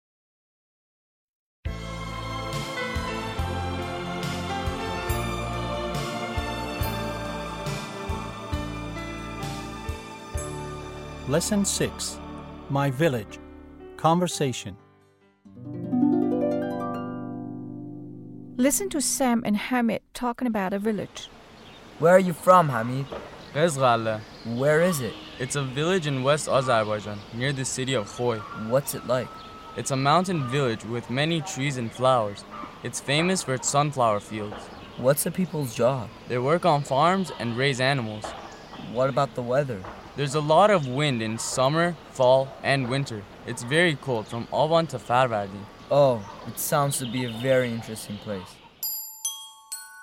8-L6-Conversation.mp3